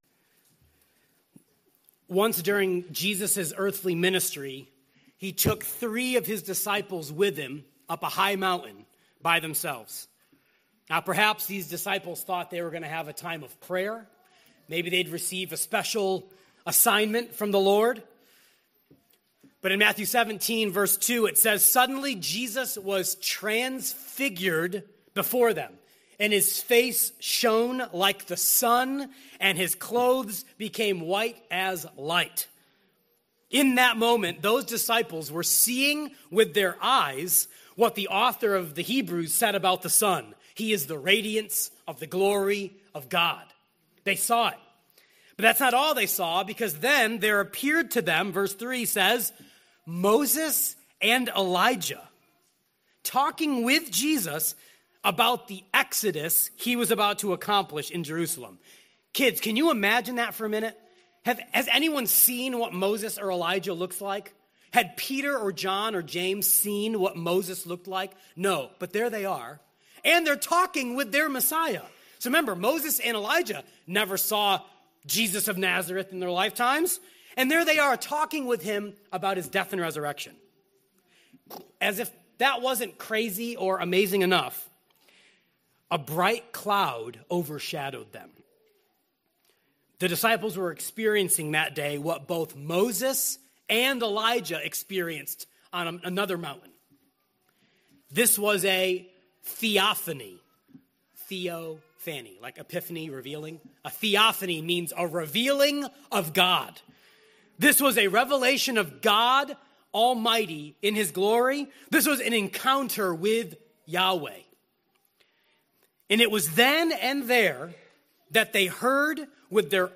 The Danger of Drift (Hebrews 2:1-4) from Emmanuel Community Church Sermons.